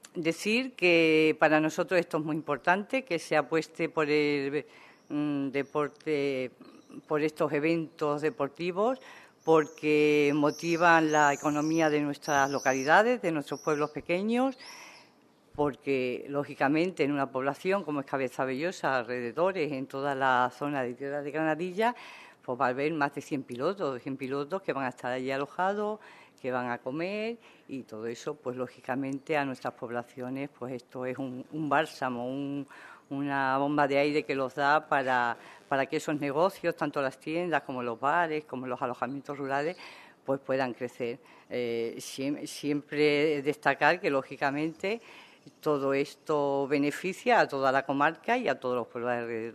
CORTES DE VOZ
Maria-Angeles-Talavan_Alcaldesa-Cabezabellosa.mp3